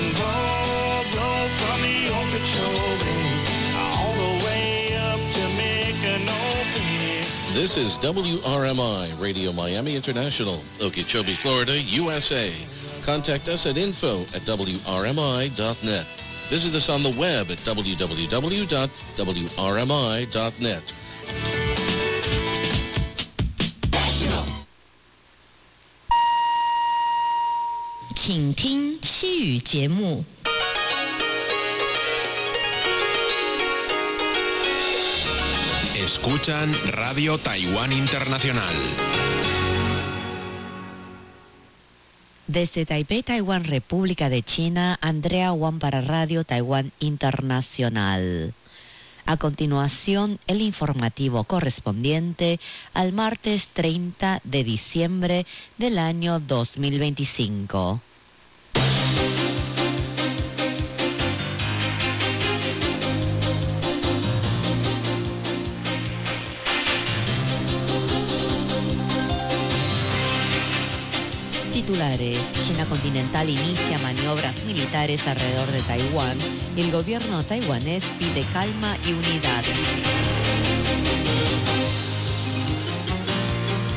And here they are signing off for the day on December 27, 2025 at 1700 UTC on 9405 kHz, again using a Kiwi SDR in Japan.